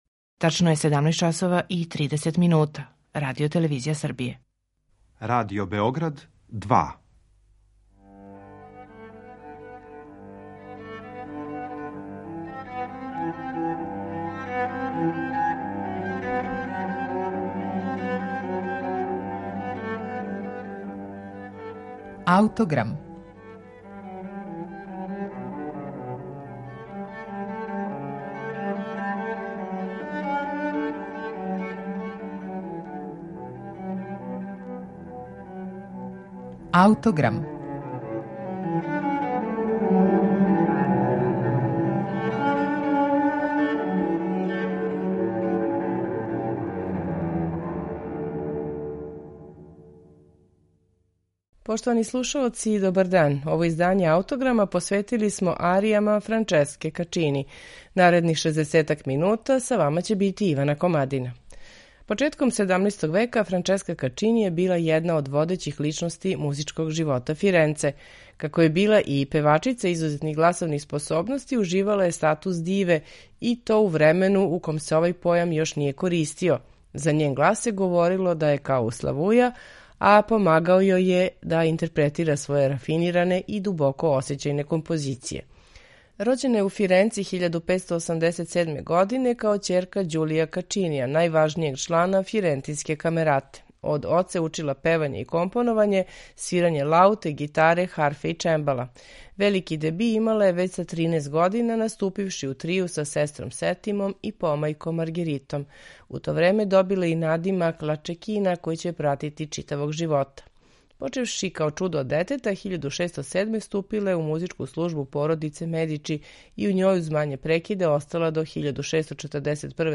сопран
виолина
чембало
виола да гамба
лаута и удараљке
теорба, цитра и барокна гитара